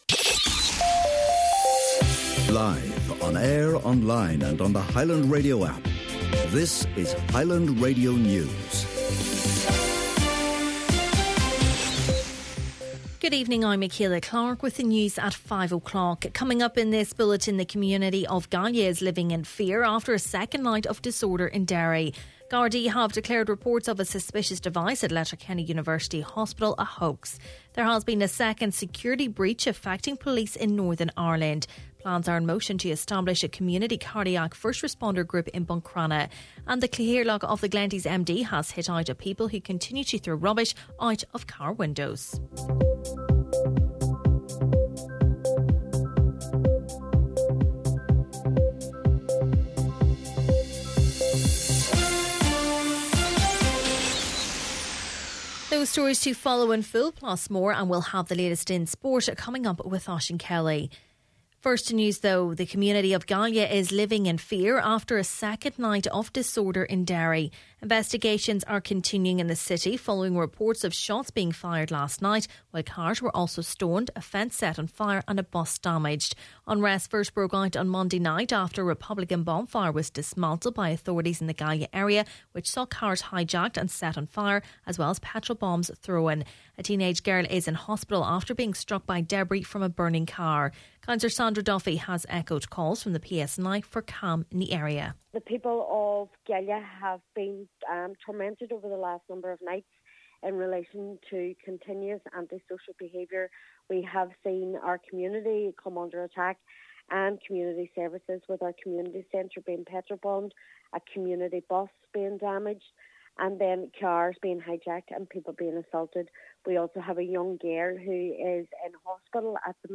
Main Evening News, Sport and Obituaries – Wednesday August 9th